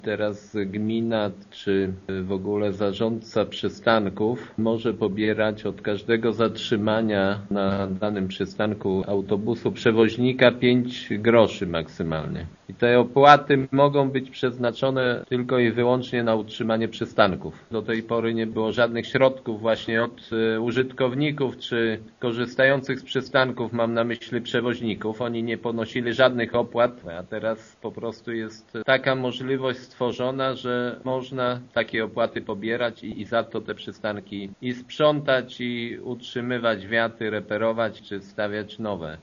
Dzięki innej decyzji radnych gmina będzie mieć pieniądze na utrzymanie przystanków. „Rada Gminy skorzystała z możliwości, jakie daje znowelizowana ustawa o transporcie publicznym” – informuje wójt Jacek Anasiewicz: